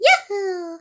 birdo_yahoo1.ogg